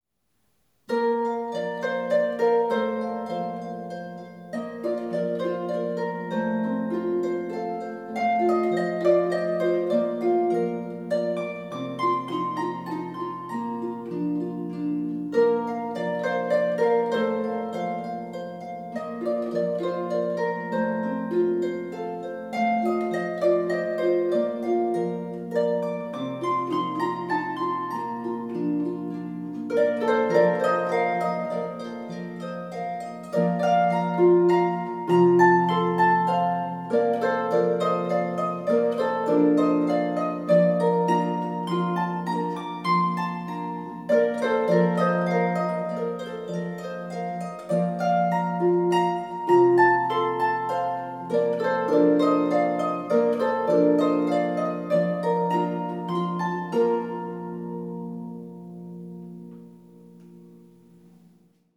for two lever or pedal harps